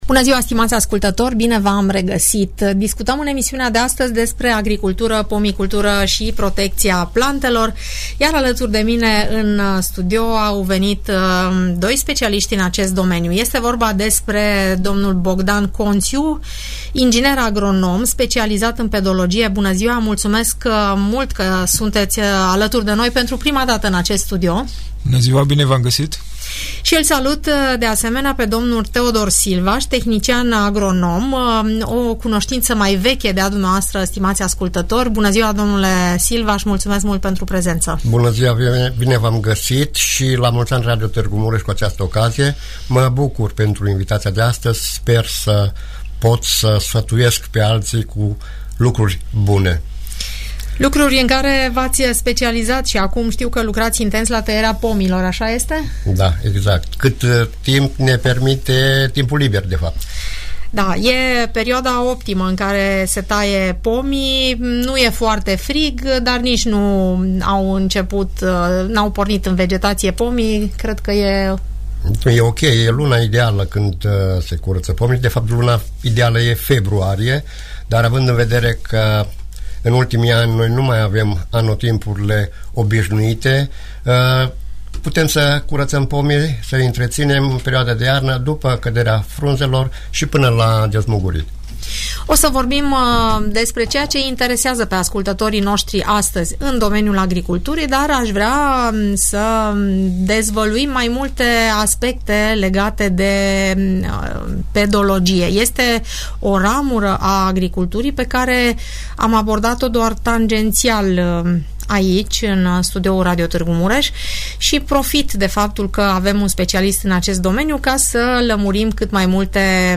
pedolog
tehnician horticol